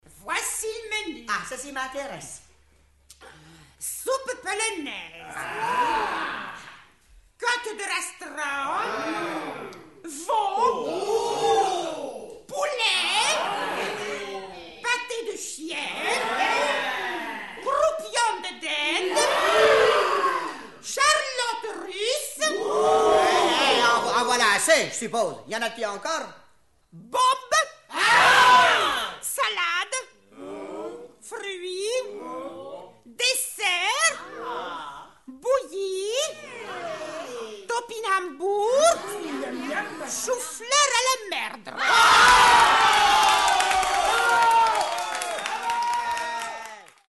Le meilleur souvenir que je garde de cette dernière catégorie reste sans aucun doute Ubu roi interprété par Jacques Dufilho, Claude Pieplu, Jean Richard et toute une bande de rigolos dans un coffret sorti des archives de l’INA.